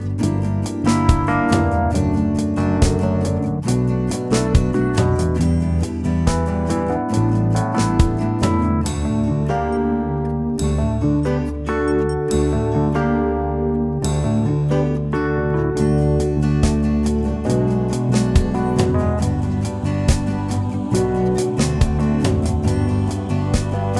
Without Saxophone Pop (1980s) 4:15 Buy £1.50